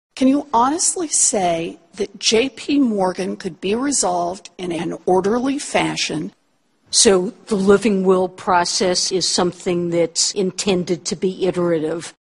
Here is Janet Yellen (69), Chair of the US Federal Reserve, described as the most powerful woman in the world, testifying before Congress: